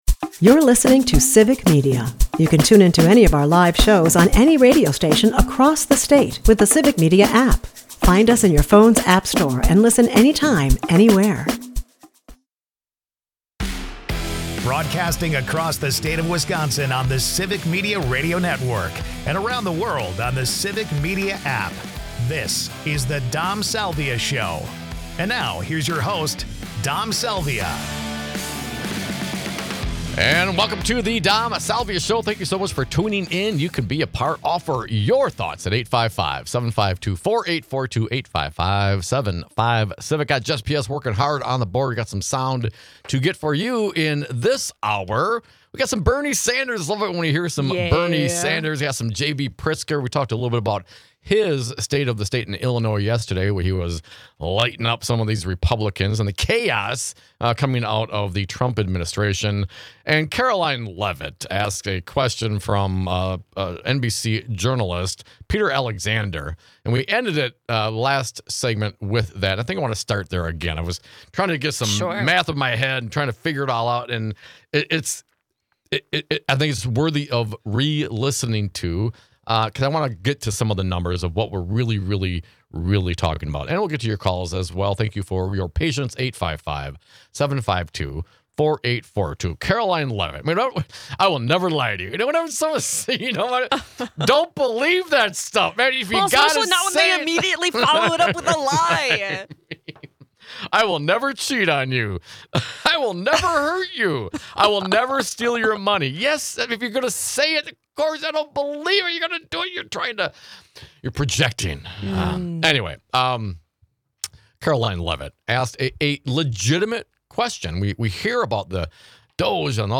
First, Senator Bernie Sanders of Vermont, delivering a 5-minute speech rebuking Trump's alignment with Putin and urging us to embrace democracy.